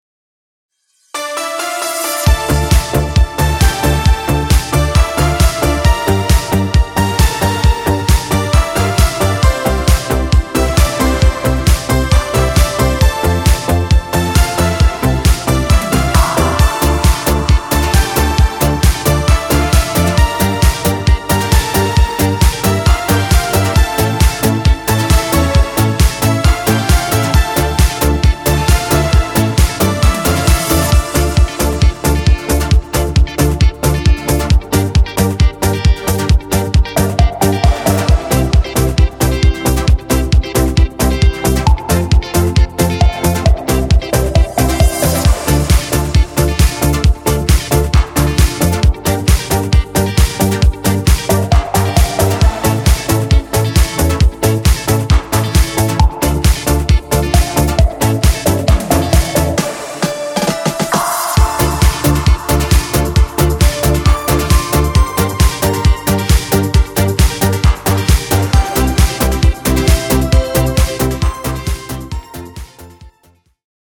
aranżacja w klimacie italo disco
Disco Polo